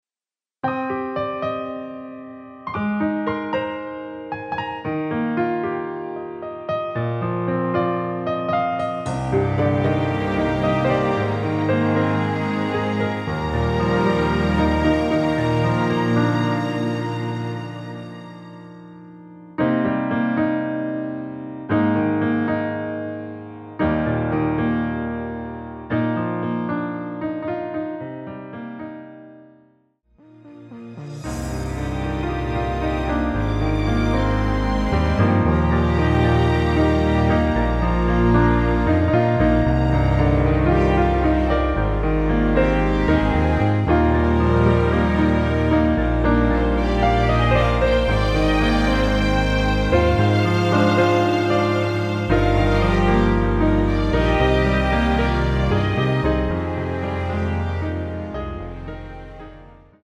Cm
◈ 곡명 옆 (-1)은 반음 내림, (+1)은 반음 올림 입니다.
앞부분30초, 뒷부분30초씩 편집해서 올려 드리고 있습니다.
중간에 음이 끈어지고 다시 나오는 이유는